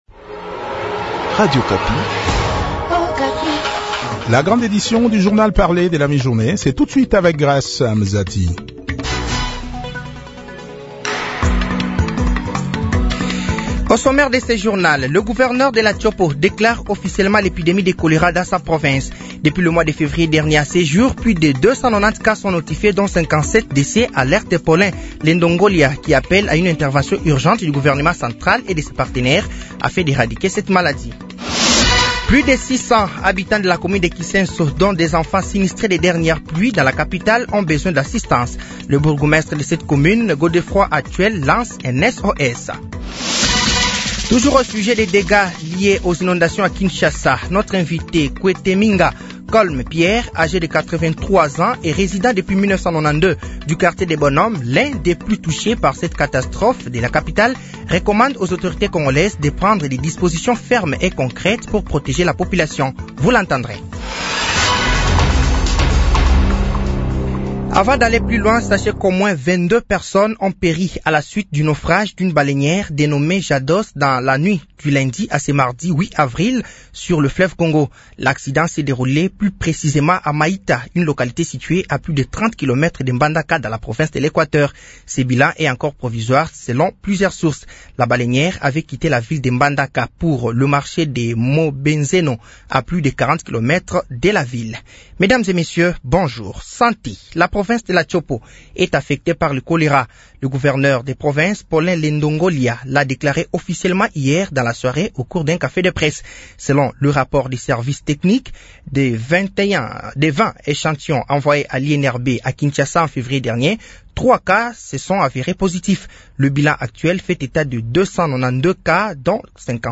Journal français de 12h de ce mardi 08 avril 2025